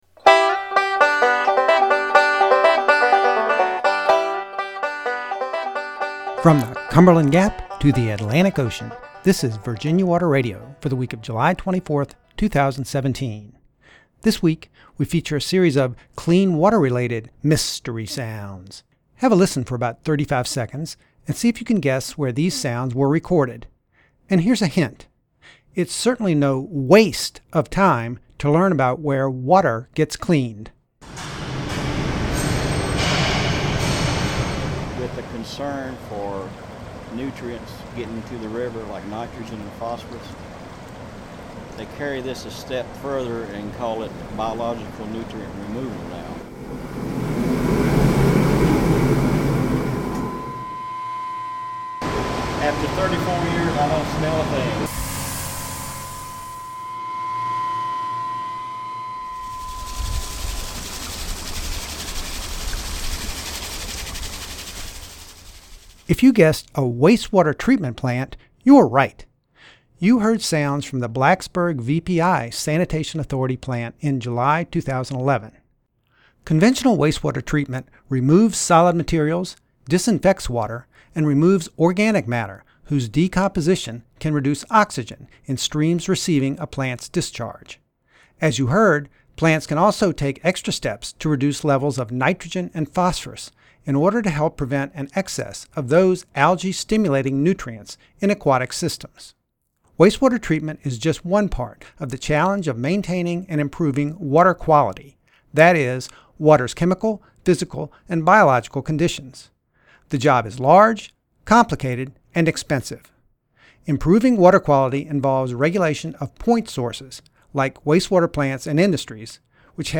The sounds in this recording were of the plant’s incinerator room, chlorination chamber, pump room (the high-pitched screech), grit removers, and algae-scrubbers on secondary clarifiers.